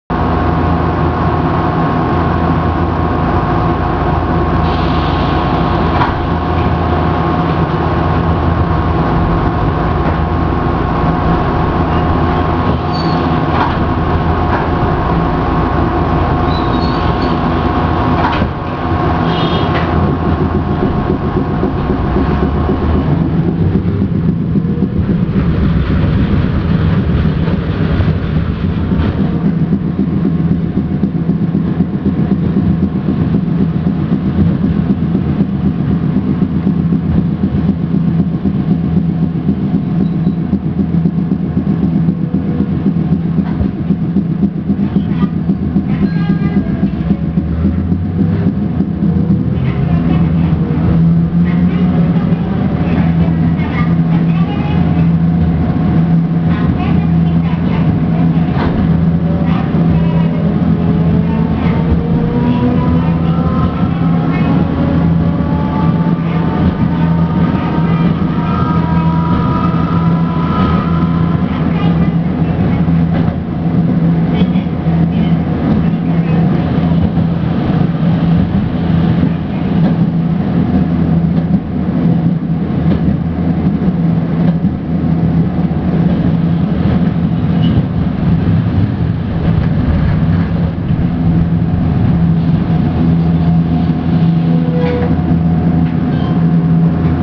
〜車両の音〜
・7000形走行音
【市内軌道線】小泉町→堀川小泉（1分37秒：529KB）
路面電車にはありがちな、音の軽めな吊り掛けモーター。特に特筆すべき音ではありません。のろのろと走っていたのであまりモーターの音は良く聞こえませんが…。